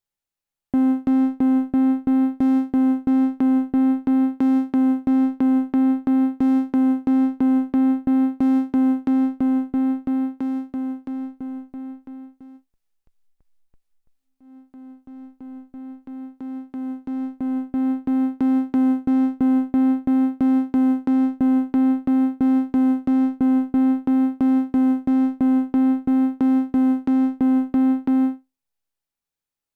Prophet-6 Dreiecksschwingungen
Ich grüße alle Jünger des Propheten, bei meinem Prophet-6 Desktop tritt folgendes Phänomen auf: Ich erstelle ein Basic Preset und drehe lediglich den Waveform-Drehregler von OSC 1 ganz nach links, stelle also eine Dreicksschwingung ein.